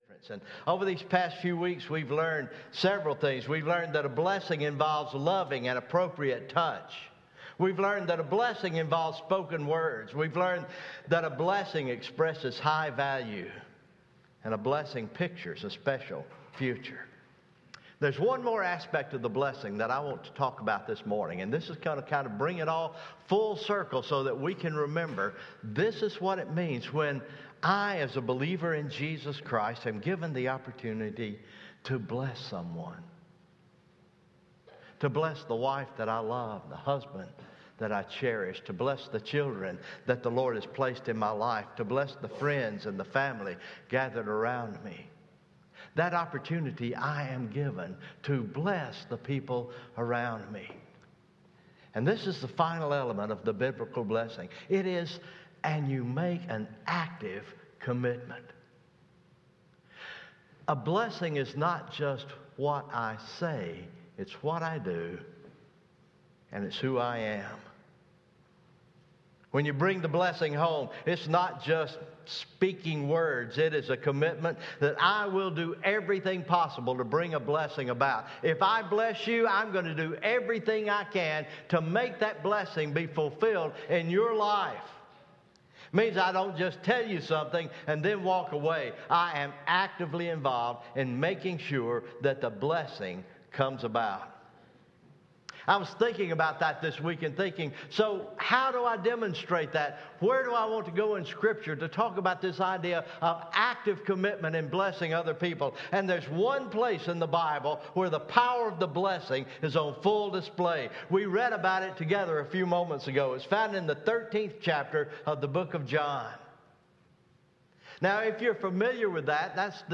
November 14, 2021 Morning Worship